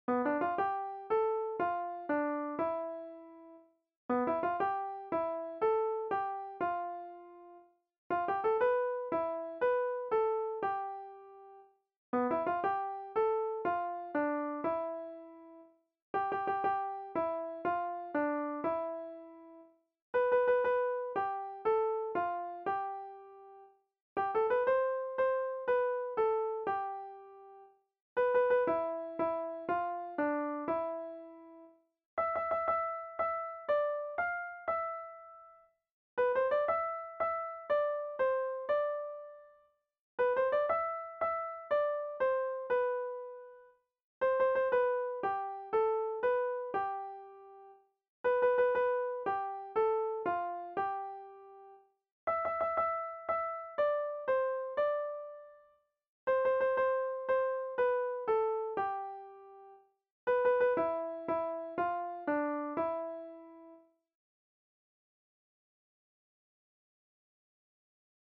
Bertso melodies - View details   To know more about this section
Kontakizunezkoa
Hamaseiko berdina, 8 puntuz eta 8 silabaz (hg) / Zortzi puntuko berdina, 16 silabaz (ip)